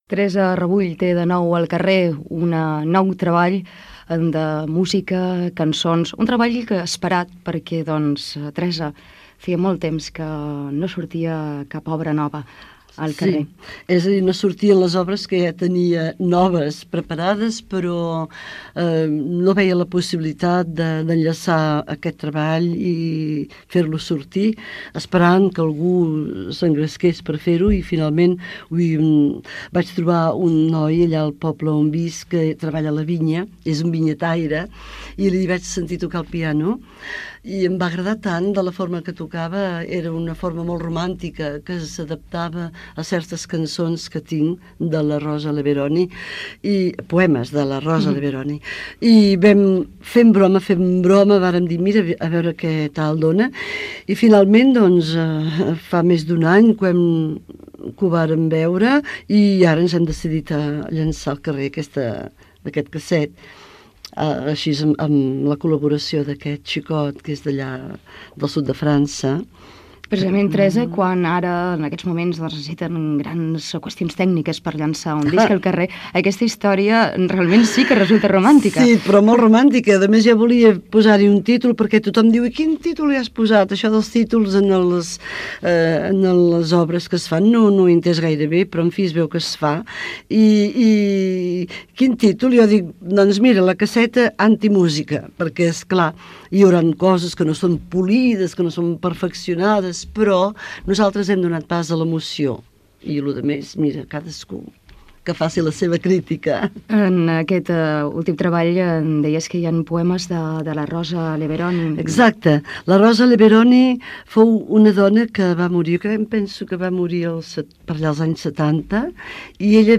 Fragment d'una entrevista a la cantautora Teresa Rebull